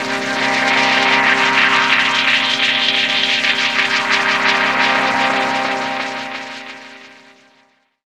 Index of /90_sSampleCDs/Chillout (ambient1&2)/09 Flutterings (pad)
Amb1n2_r_flutter_c.wav